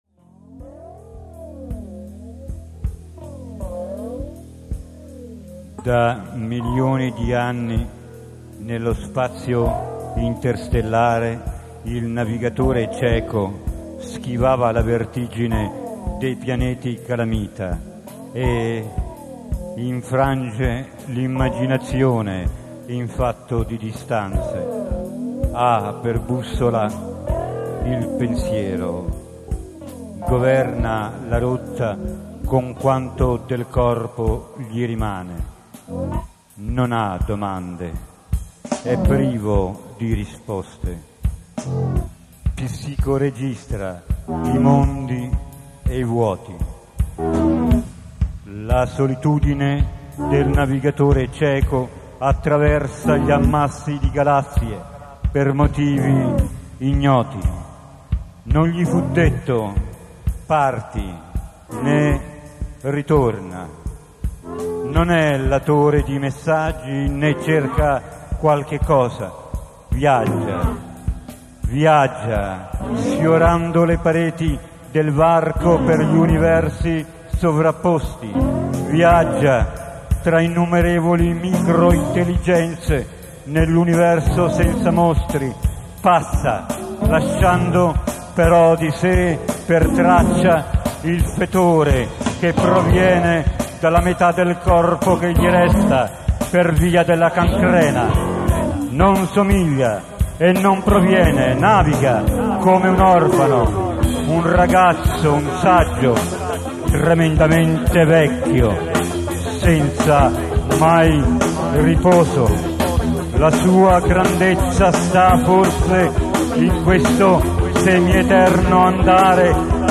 AUDIO LIVE
The X-Raymen
Scandellara Rock Festival